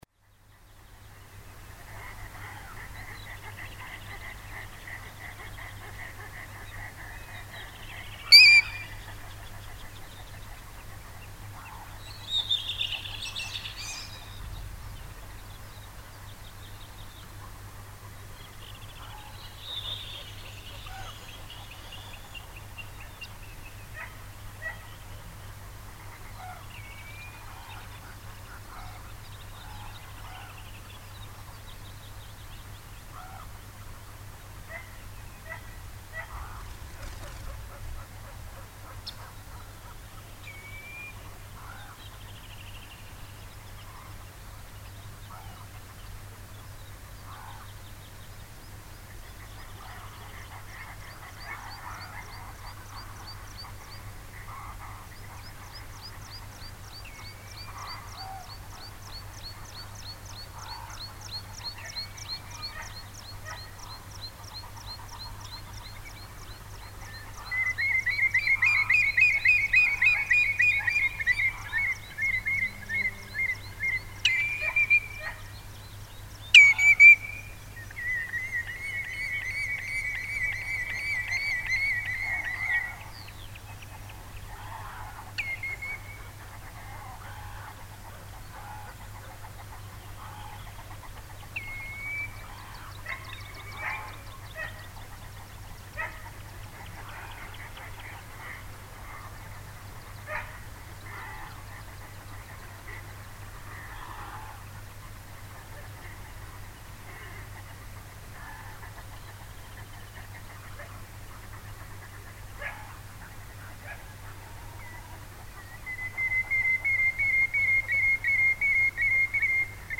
Var það ákaflega rólegur tími enda ekki mikil bílaumferð eftir Barðastrandarvegi (62). Þann 24 júní vaknaði ég um kl. 6 til að hljóðrita fyrir utan hús. Mikið var um mófugla, vaðfugla og spörfugla. Uppi í klettum fjallanna umhverfis mátti greinilega heyra mikið mávahjal.
Hér er á ferðinni mjög lágstemmd upptaka.
Þetta er líklega síðasta upptakan sem ég tók upp á Sony TC-D5M kassettutækið áður en ég fór að tileinka mér stafræna upptökutækni. Það má því heyra talsvert grunnsuð en það suð mælist u.þ.b. -60 db í kassettutækjum á meðan það er rúmlega -100 db á stafrænum tækjum.
Hljóðnemar voru Sennheiser ME62 og snúrur 1,5 metra langar CAT6 strengir.